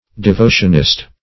Search Result for " devotionist" : The Collaborative International Dictionary of English v.0.48: Devotionalist \De*vo"tion*al*ist\, Devotionist \De*vo"tion*ist\, n. One given to devotion, esp. to excessive formal devotion.